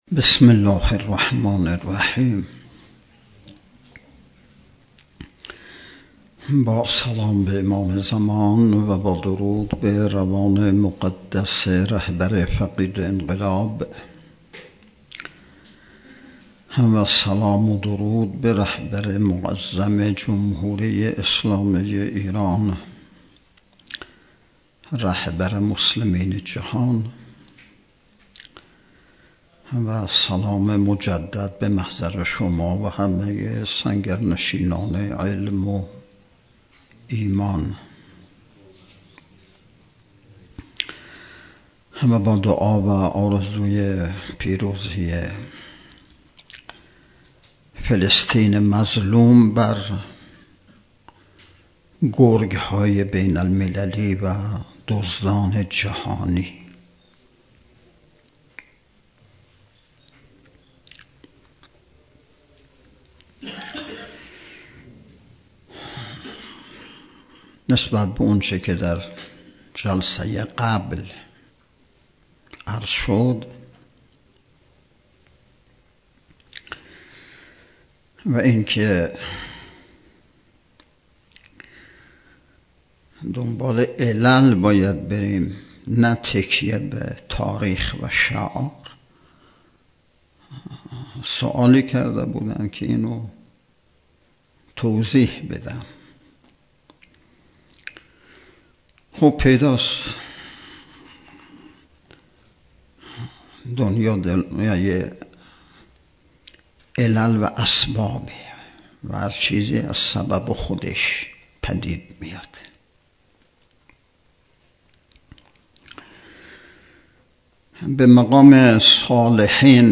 دومین نشست ارکان شبکه تربیتی صالحین بسیج با موضوع تربیت جوان مؤمن انقلابی پای کار، صبح امروز ( ۱۸ آبان) با حضور و سخنرانی نماینده ولی فقیه در استان، برگزار شد.